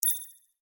Epic Holographic User Interface Click 6.wav